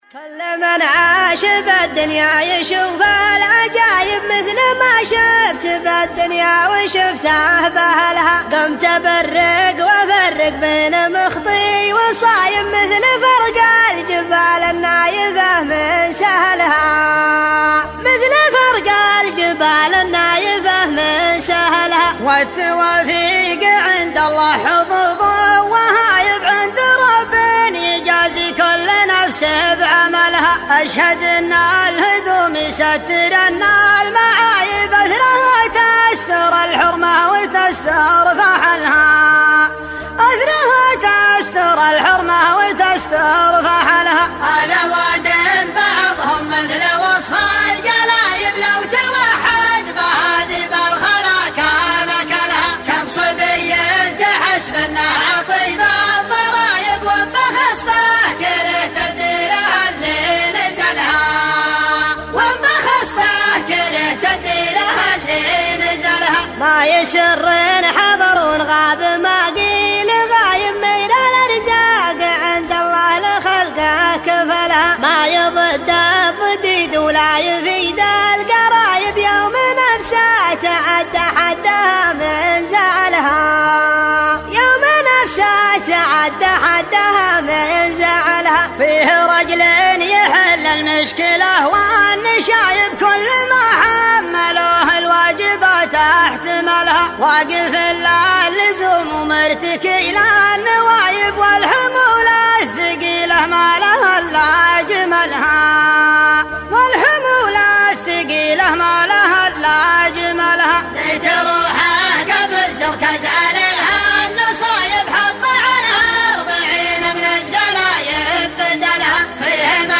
دويتو